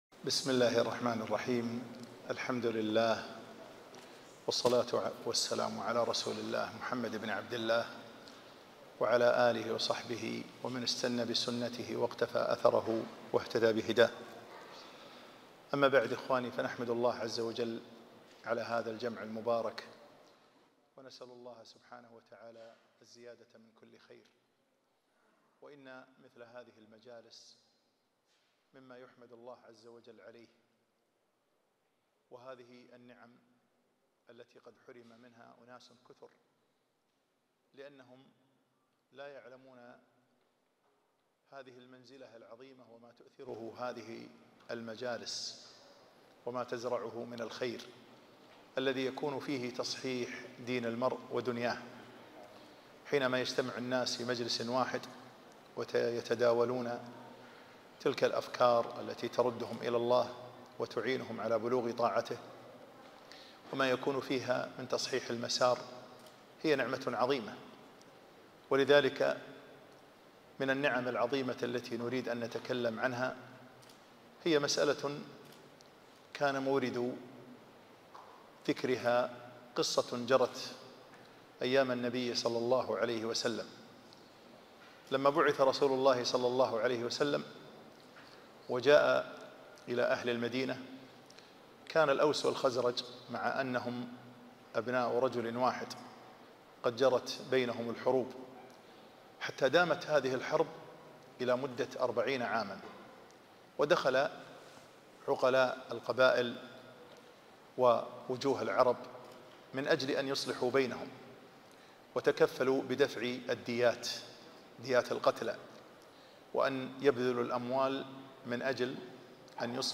محاضرة - نعم لا يغفل عنها